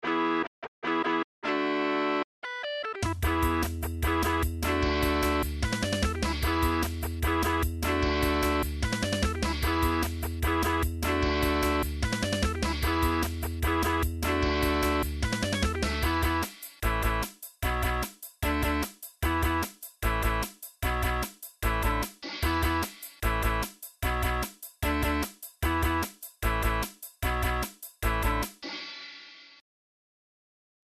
ちょっと成果のほどを・・・うｐ（打ち込み音源）
ちょっとしたライブの始まりかなんかで使えそうな曲。
ベースを目立たせるための曲ｗ